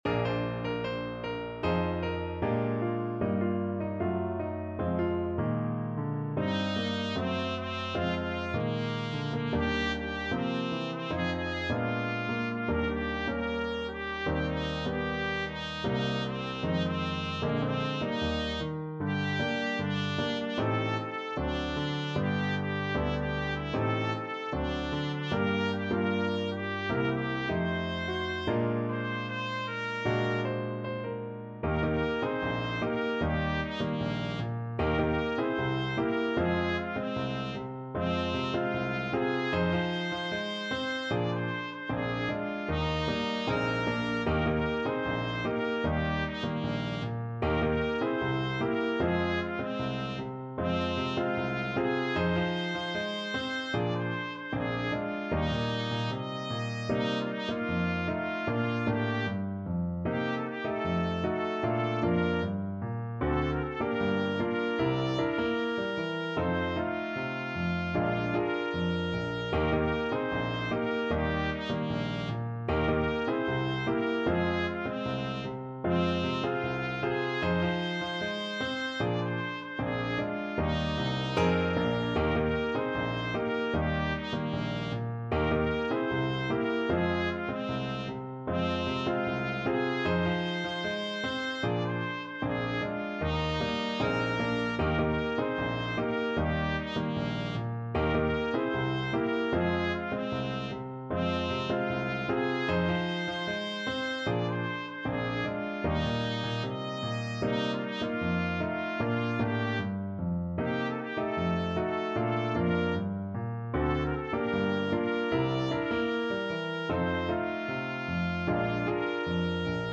2/2 (View more 2/2 Music)
Pop (View more Pop Trumpet Music)